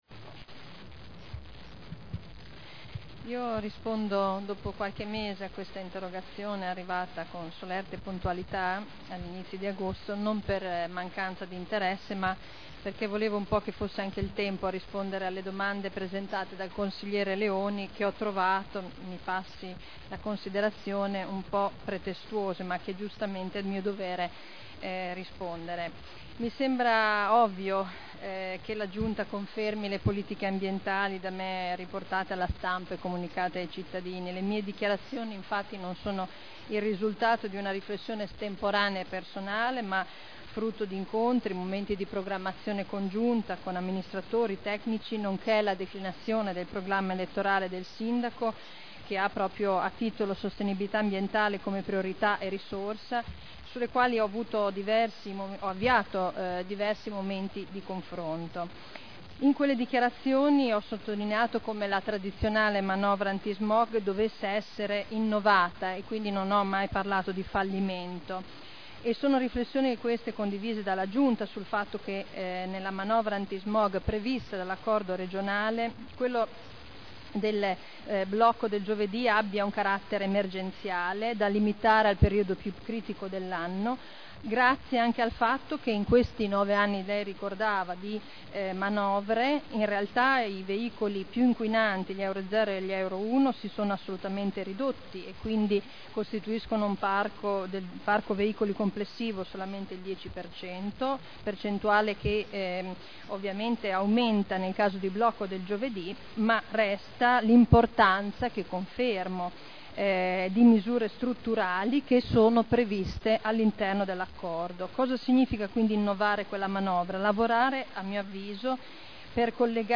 Seduta del 30/11/2009. Politiche ambientali.